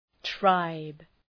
Προφορά
{traıb}